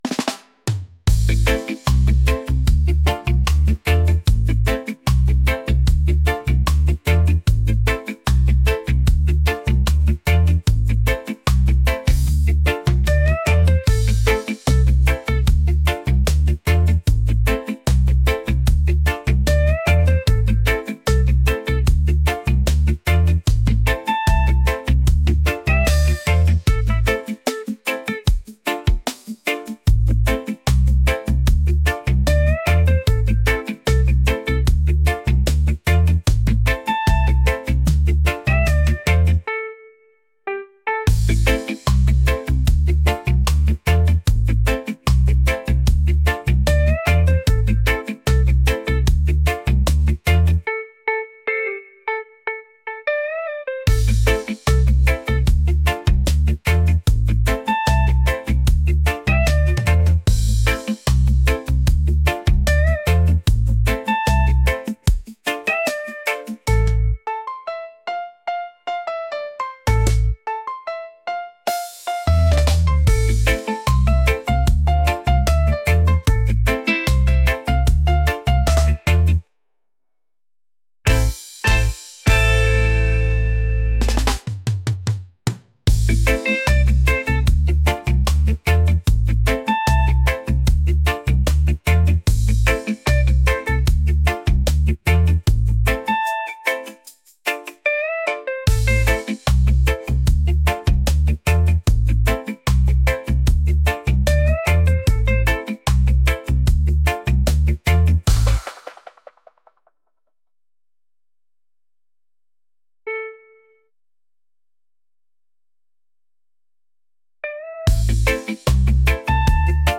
groovy | reggae